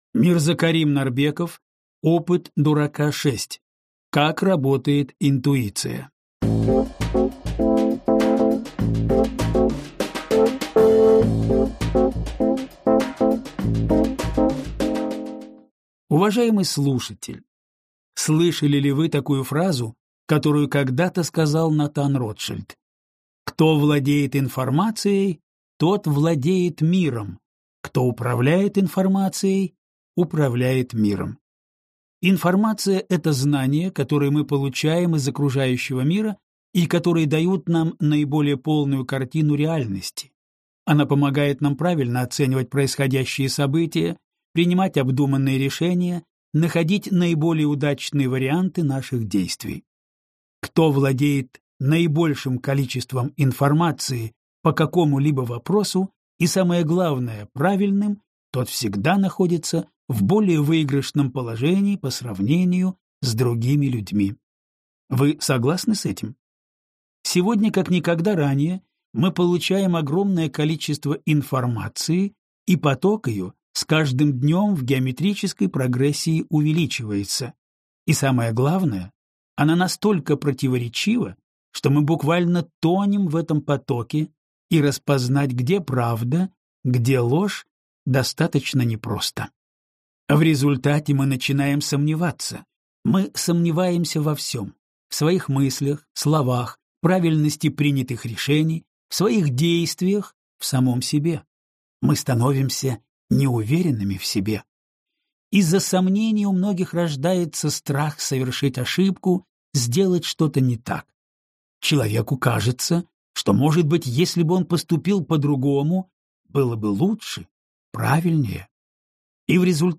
Аудиокнига Опыт дурака 6. Как работает интуиция | Библиотека аудиокниг